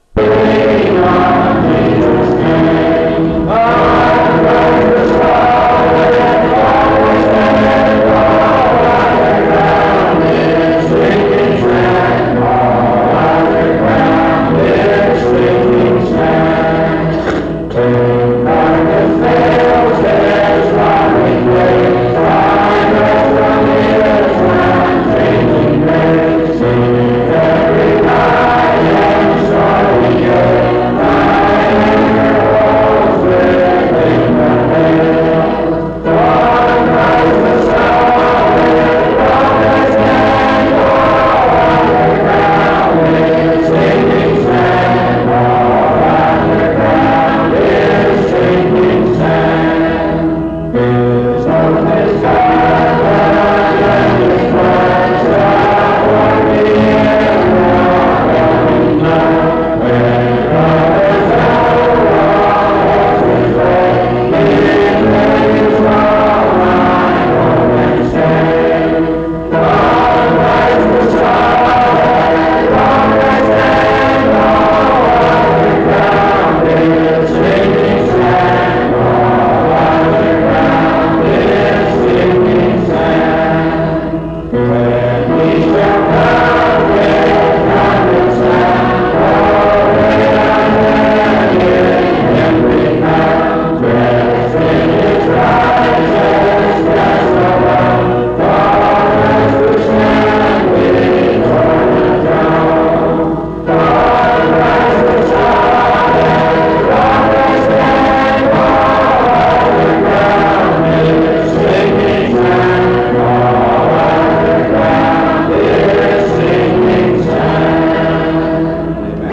Solid Rock Item cb03e1b0db2e51bb25c8651b2bbae6afd1000aa3.mp3 Title Solid Rock Creator Congregation Description This recording is from the Monongalia Tri-District Sing.